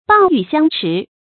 注音：ㄅㄤˋ ㄧㄩˋ ㄒㄧㄤ ㄔㄧˊ
蚌鷸相持的讀法